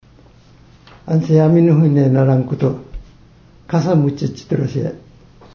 沖縄県那覇市首里の原因・理由表現会話例
■年齢差がある会話